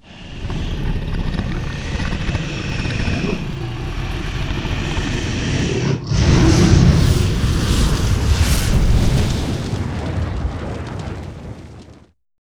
firecloak.wav